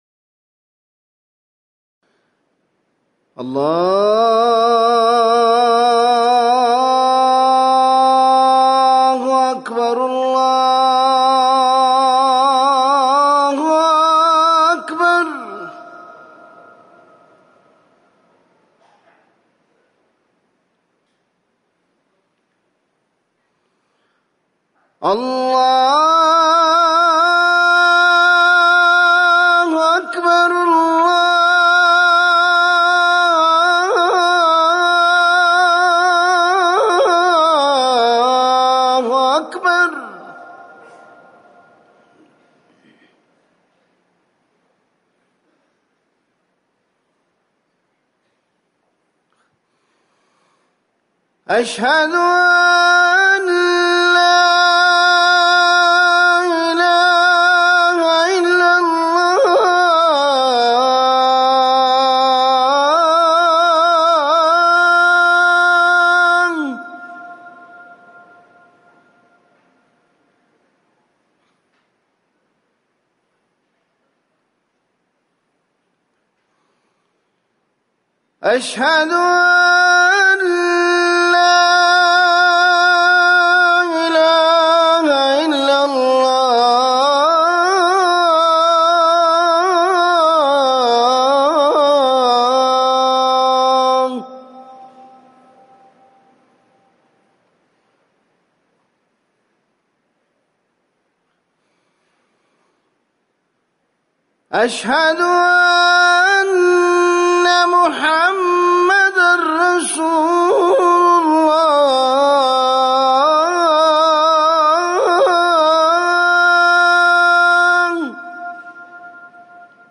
أذان الظهر
تاريخ النشر ١٨ محرم ١٤٤١ هـ المكان: المسجد النبوي الشيخ